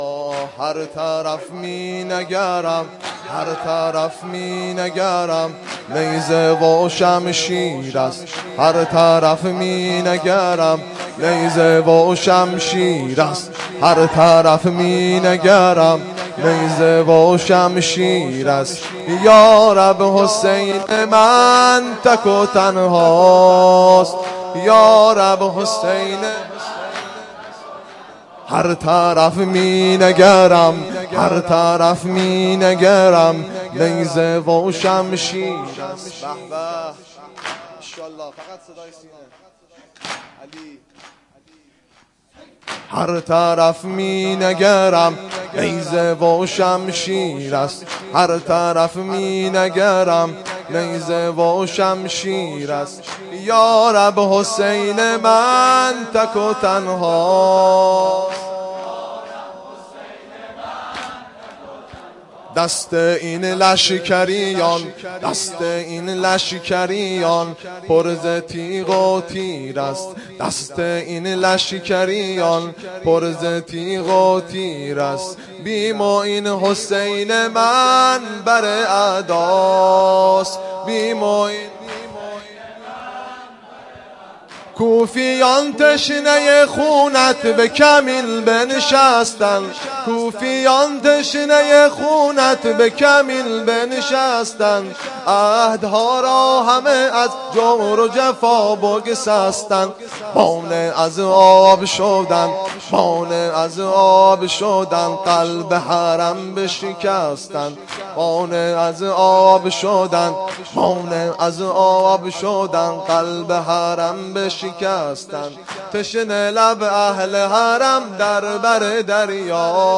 واحد شور